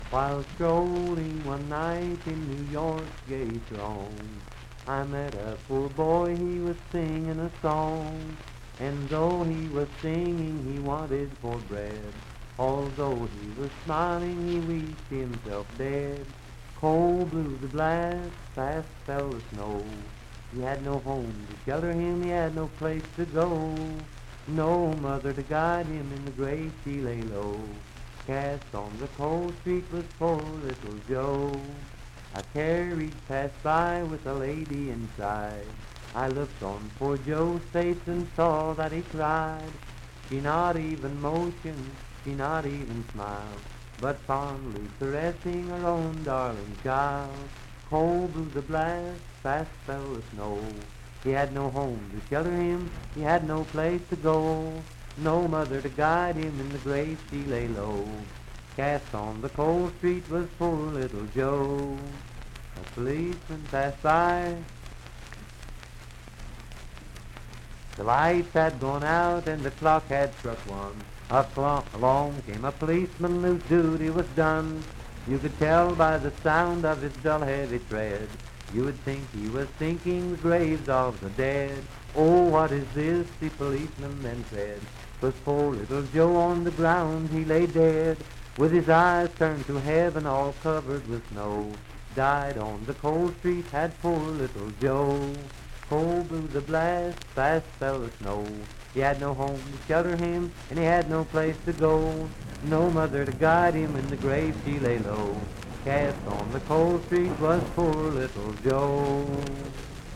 Unaccompanied vocal music
Verse-refrain 4(4) & R(4).
Voice (sung)
Parkersburg (W. Va.), Wood County (W. Va.)